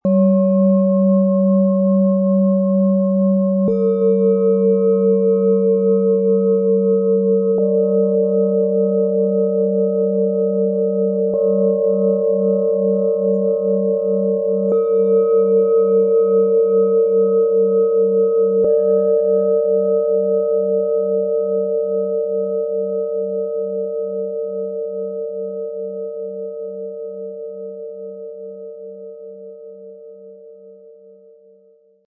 Erdung spüren, innere Balance leben und Vertrauen stärken - Set aus 3 Planetenschalen, Ø 10,3-16,6 cm, 1,38 kg
Im Sound-Player - Jetzt reinhören können Sie den Original-Ton genau dieser Schalen anhören. Das Zusammenspiel von Erdung, Geborgenheit und Stabilität schenkt einen warmen, tiefen Klang, der innere Ruhe und Klarheit entstehen lässt.
Das Erscheinungsbild passend zu ihrem erdigen, ausgleichenden Klang.
Tiefster Ton: Tageston, Pluto
Mittlerer Ton: Mond
Höchster Ton: Saturn
MaterialBronze